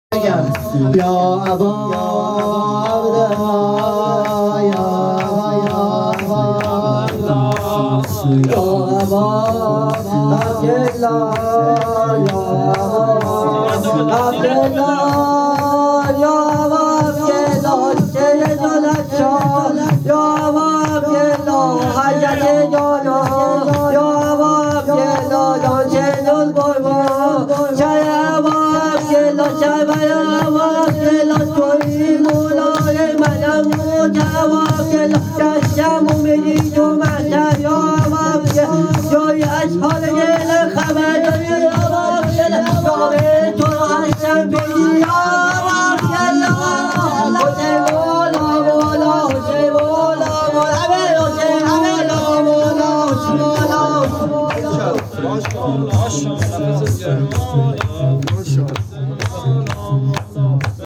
هیئت گریه کنان علی اکبر تهران